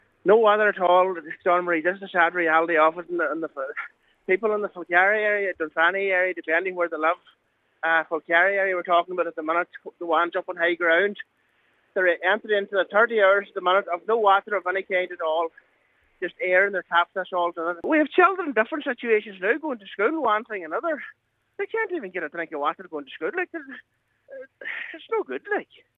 Cllr Michael McClafferty says people are at their wits’ end: